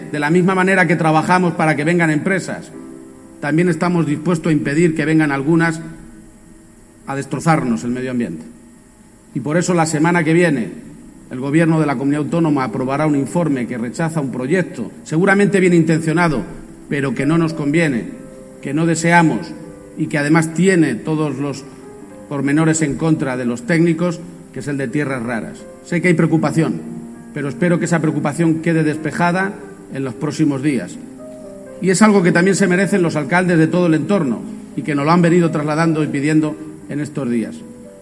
corte_presidente-tierras_raras.mp3